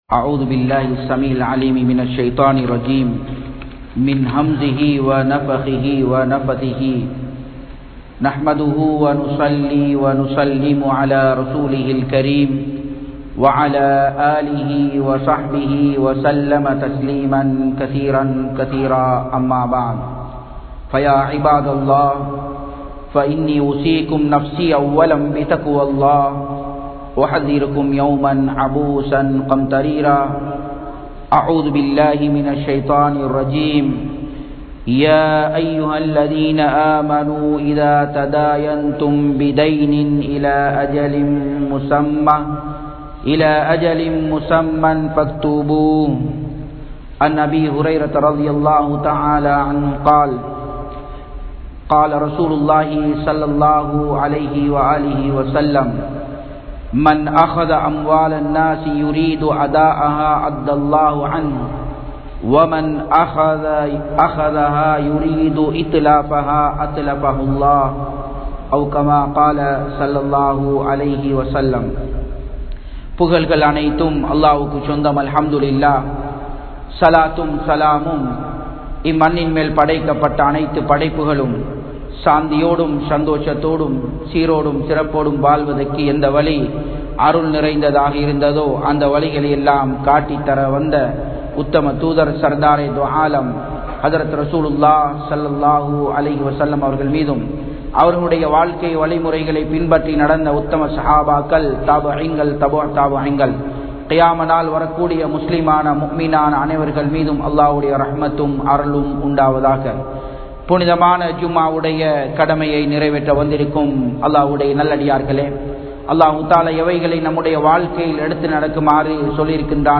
Kadanai Niraiveattravillaiya? (கடனை நிறைவேற்றவில்லையா?) | Audio Bayans | All Ceylon Muslim Youth Community | Addalaichenai
Japan, Nagoya Port Jumua Masjidh 2017-10-06 Tamil Download